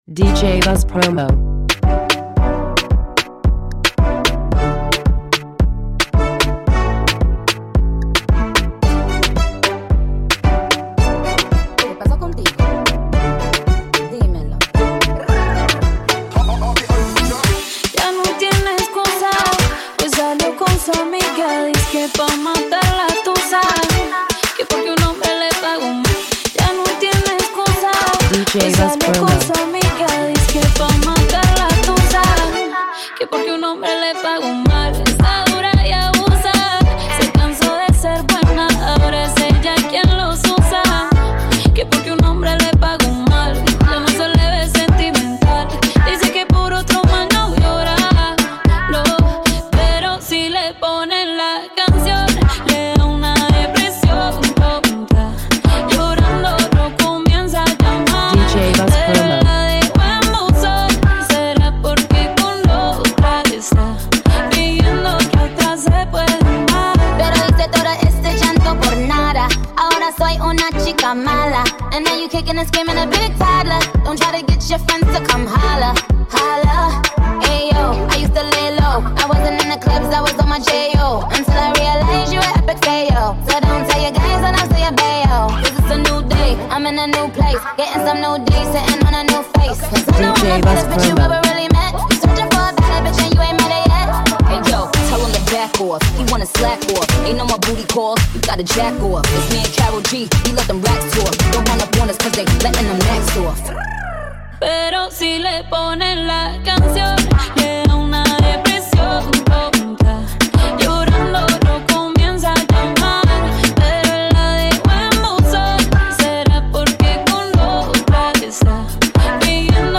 Caliente!